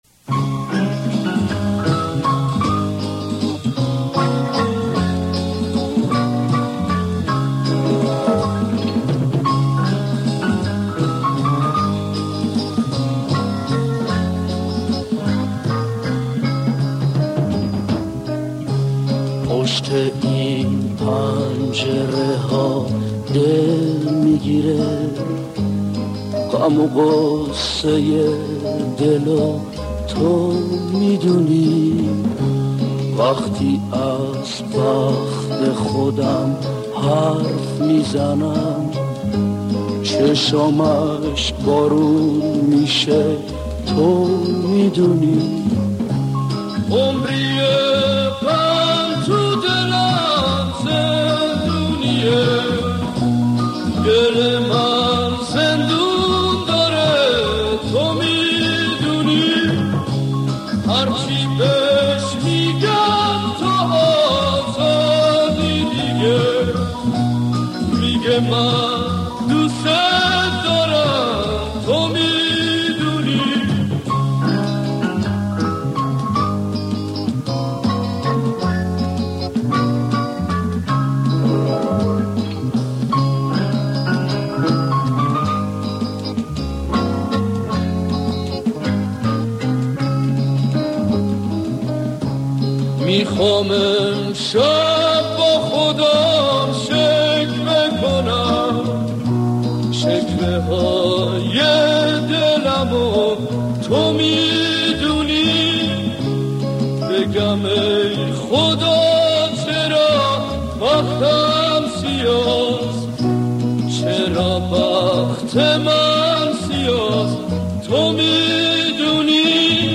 آهنگ قدیمی
غمگین
آهنگ قدیمی غمگین و بیادماندنی